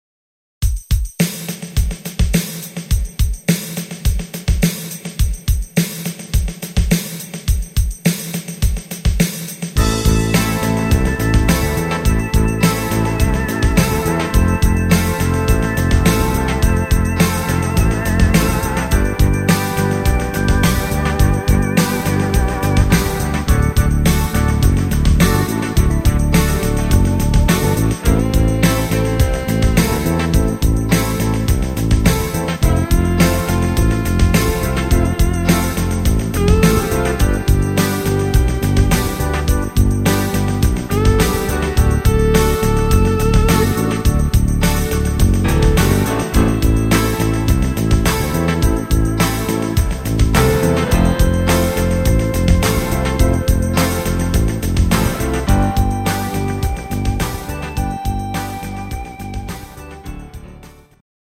instrumental Piano